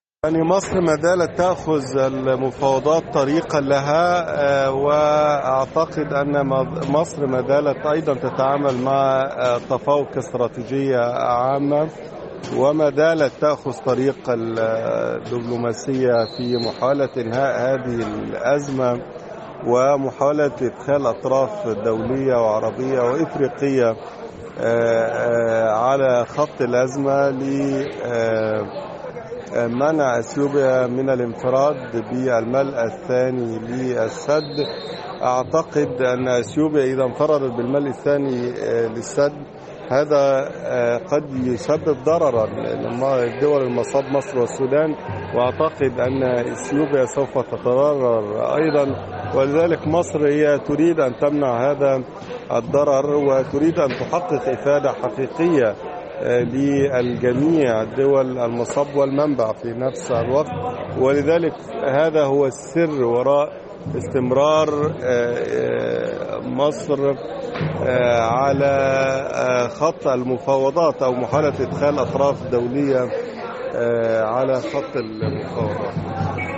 حوار